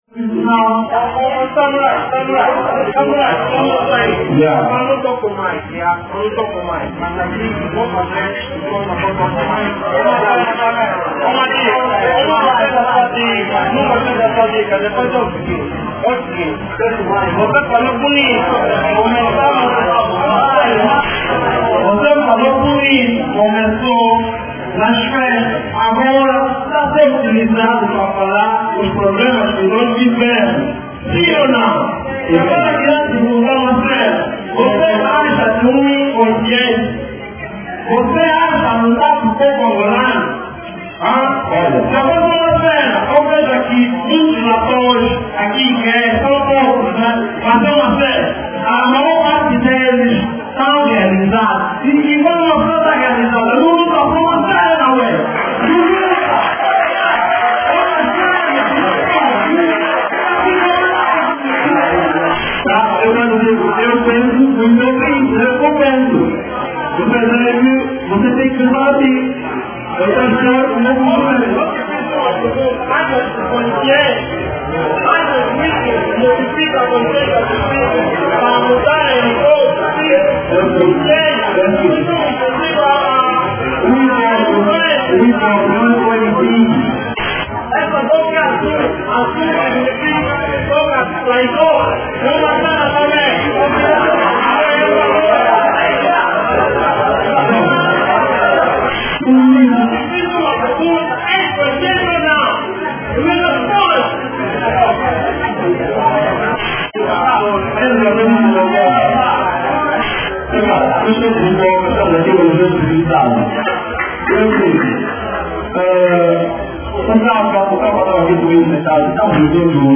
Palestra (1ª parte)